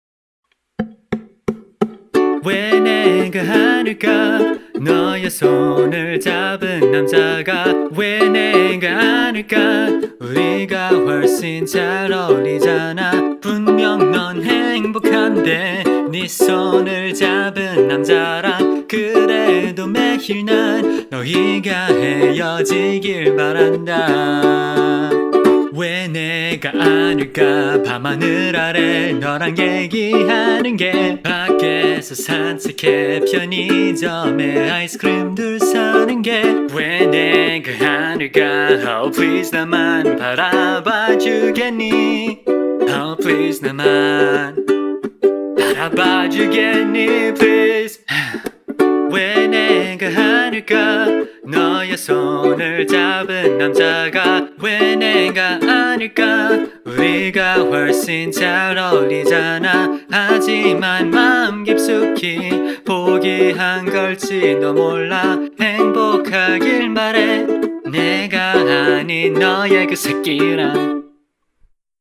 BPM175